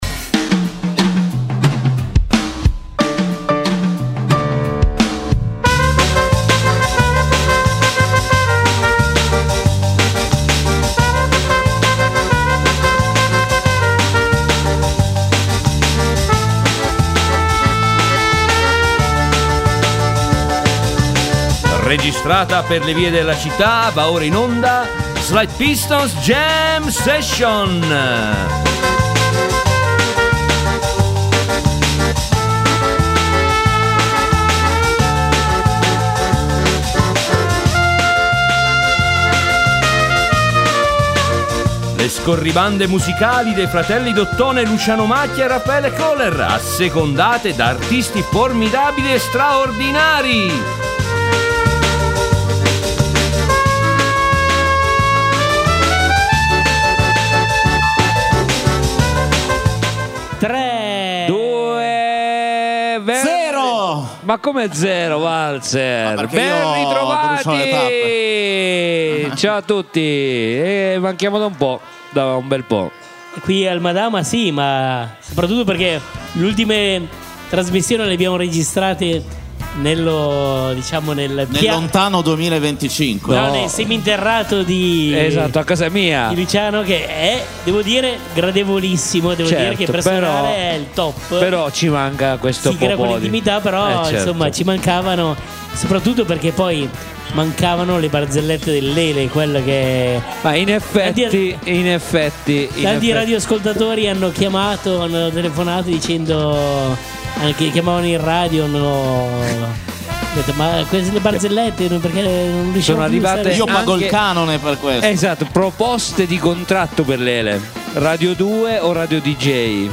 In onda le scorribande musicali dei due suonatori d’ottone in giro per la città, assecondate da artisti formidabili e straordinari.